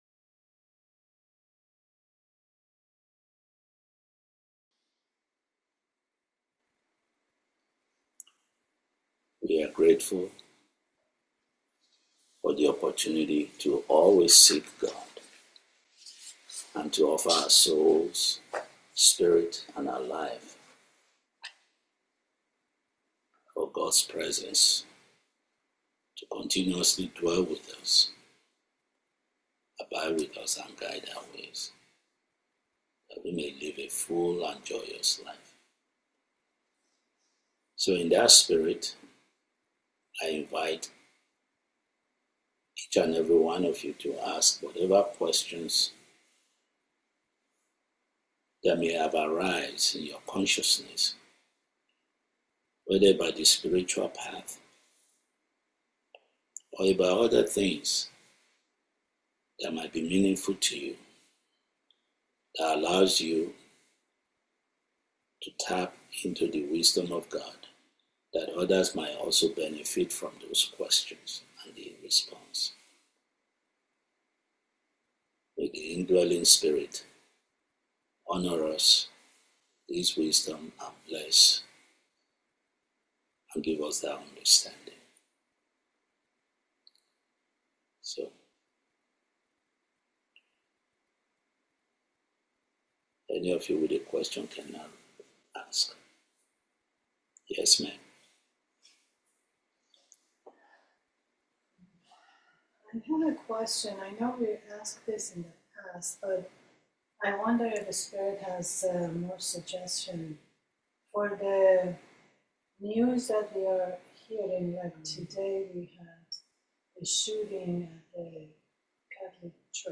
August 2025 Satsang